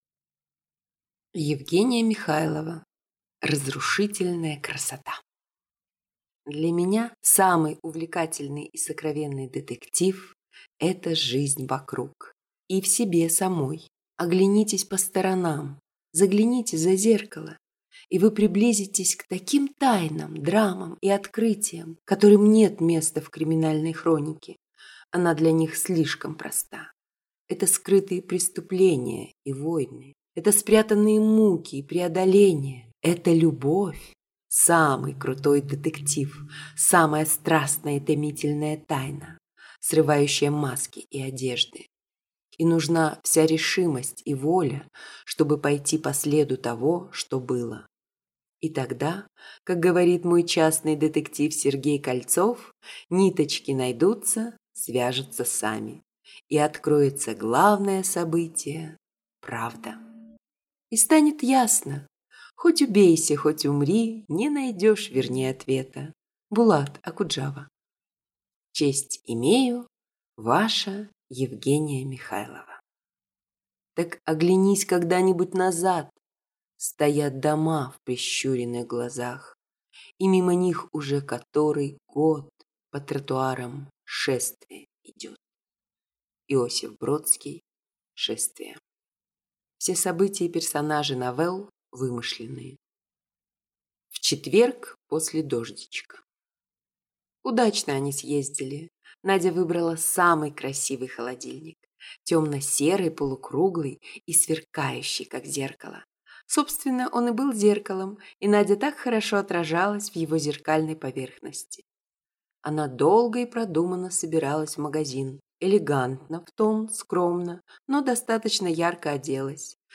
Прослушать фрагмент аудиокниги Разрушительная красота (сборник) Евгения Михайлова Произведений: 28 Скачать бесплатно книгу Скачать в MP3 Вы скачиваете фрагмент книги, предоставленный издательством